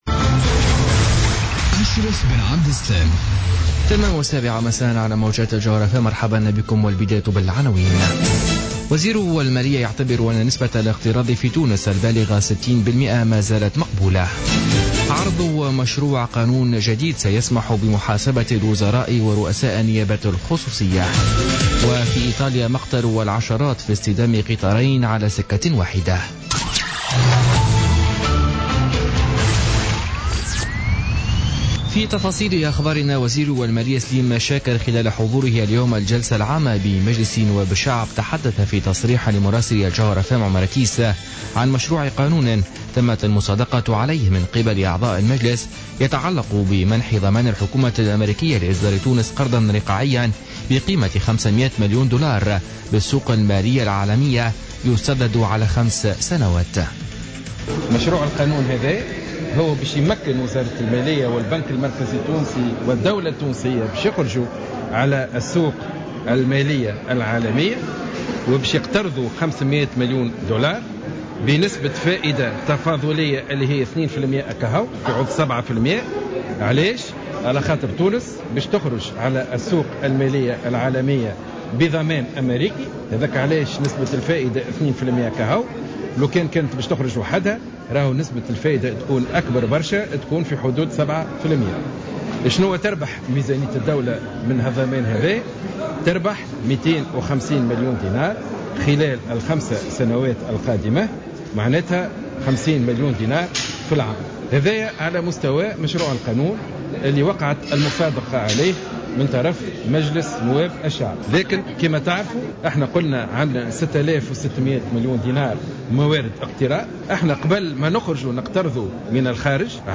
Journal Info 19h00 du mardi 12 juillet 2016